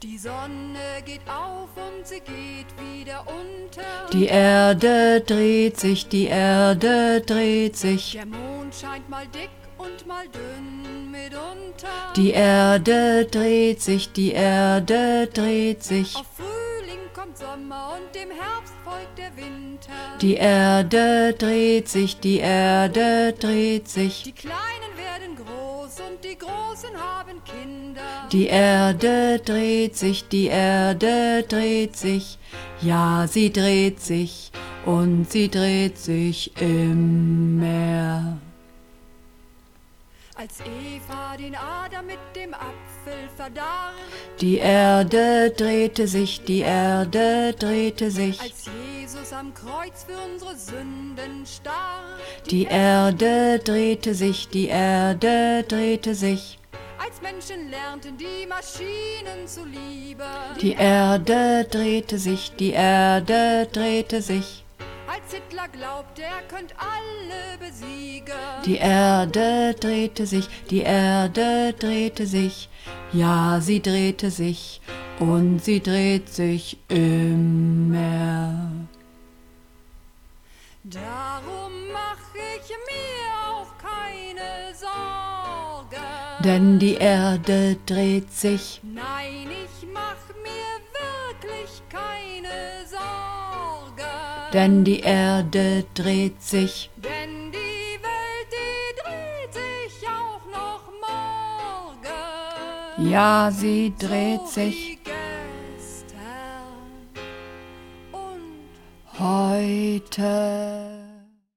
Übungsaufnahmen - Immer